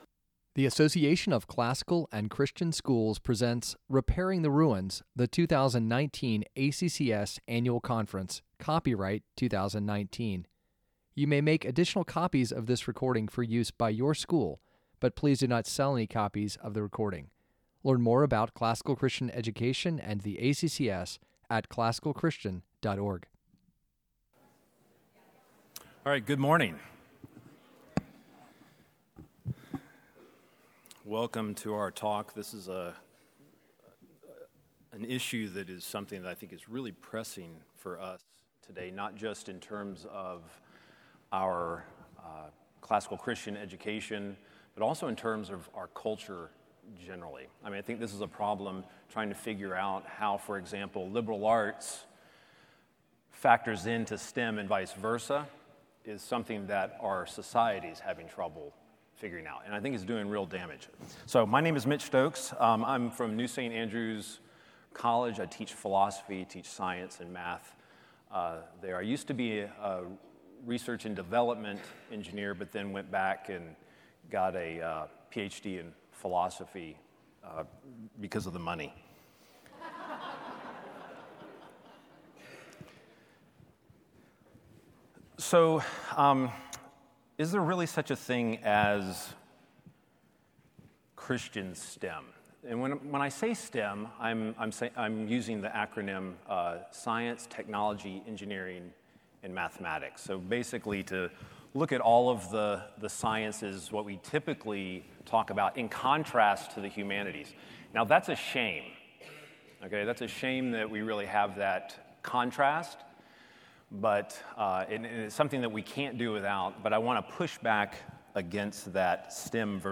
2019 Workshop Talk | 58:41 | All Grade Levels, Math, Science
Additional Materials The Association of Classical & Christian Schools presents Repairing the Ruins, the ACCS annual conference, copyright ACCS.